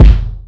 Kick (14).wav